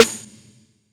SDF_SNR.wav